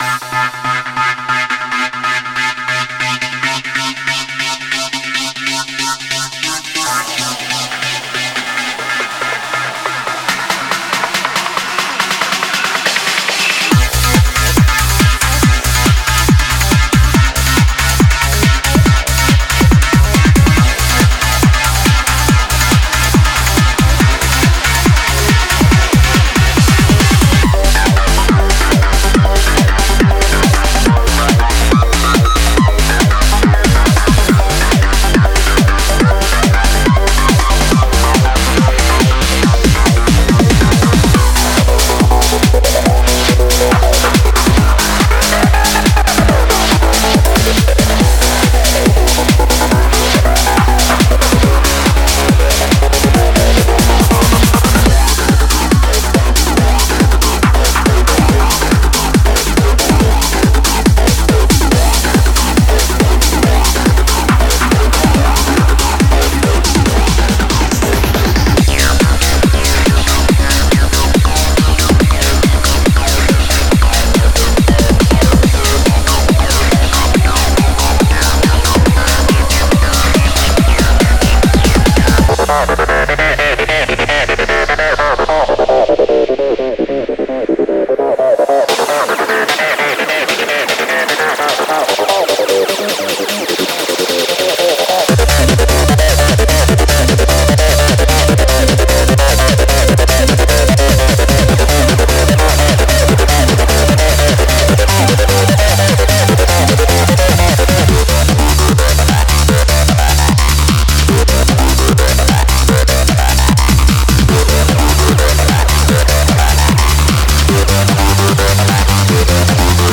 Genre:Trance
デモサウンドはコチラ↓